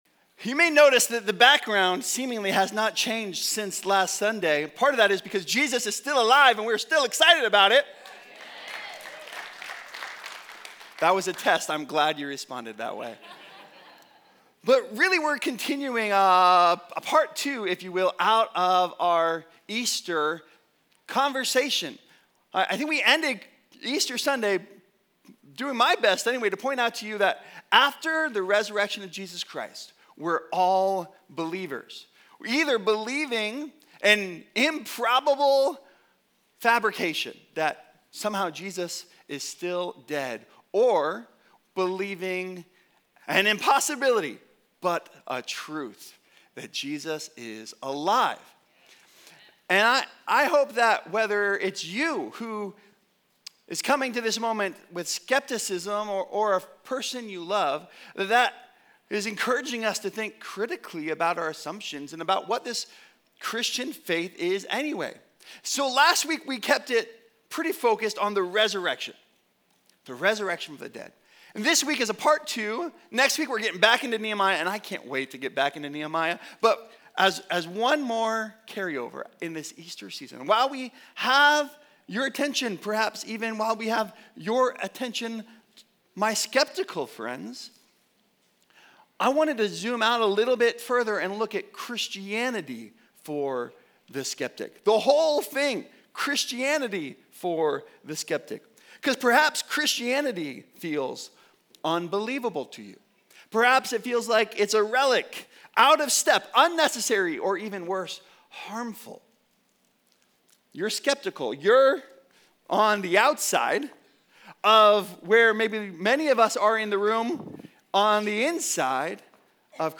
Christianity for the Skeptic | Stand-alone - HP Campus Sermons